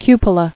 [cu·po·la]